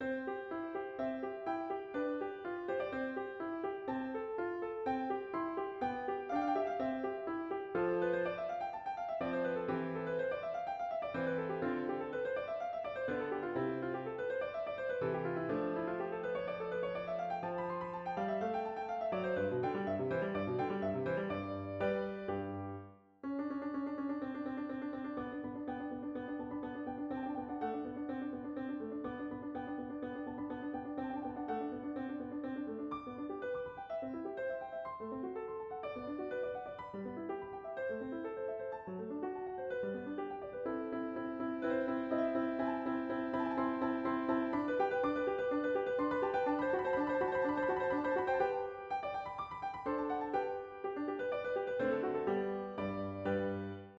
例えば、冒頭で紹介したモーツアルトのソナタ、淡々とパラパラ端正な音が続き、途中で左手の音が細かくなる箇所があります。